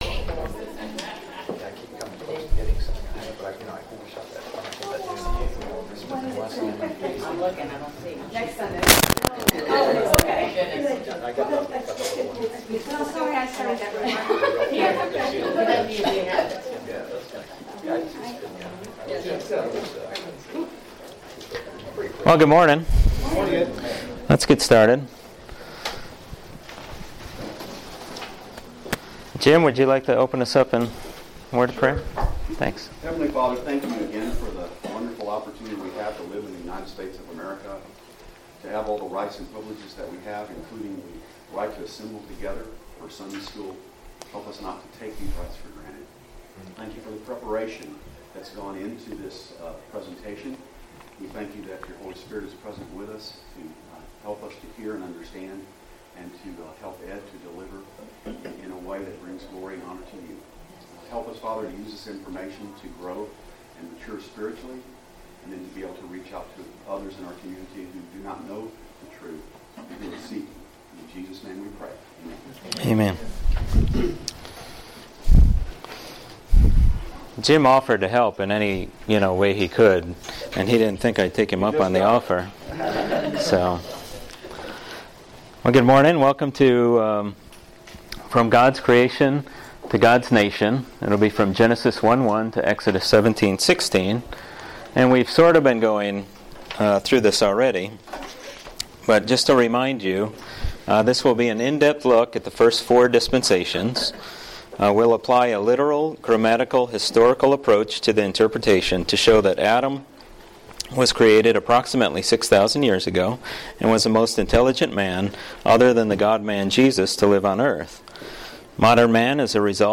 Sermons
Guest Speaker